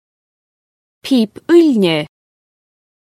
Amazon AWS (pronunciation: pìob-uilne).